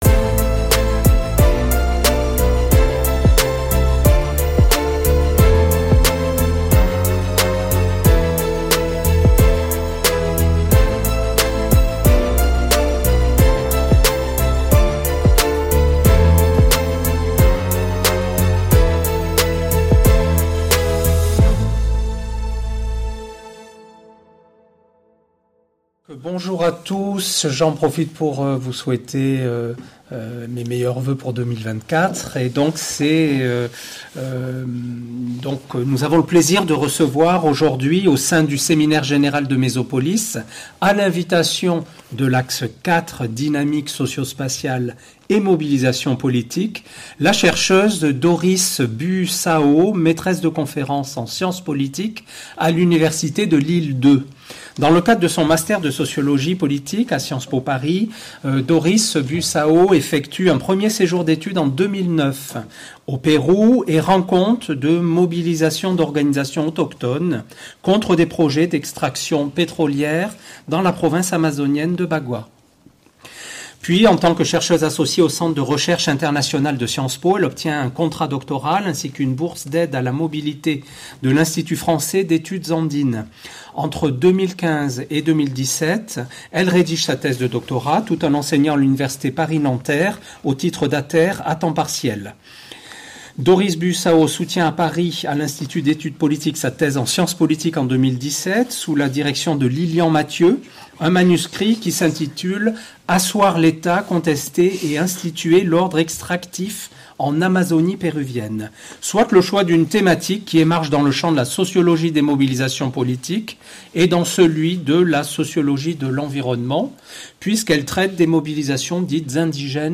Séminaire général du MESOPOLHIS, vendredi 12 janvier 2024.